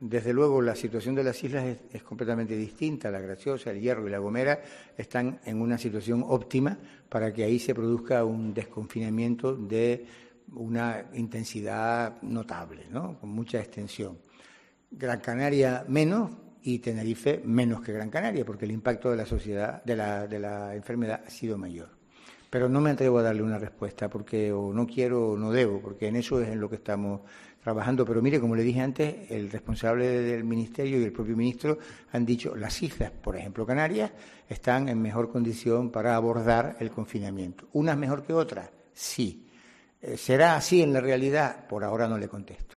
Julio Pérez, portavoz del Gobierno de Canarias
Pérez ha incidido este jueves, en la rueda de prensa telemática tras el Consejo de Gobierno, en el mensaje que se ha trasladado ya desde el Gobierno central en cuanto a que "la desescalada se adaptará a cada territorio pero deberá ser coordinada".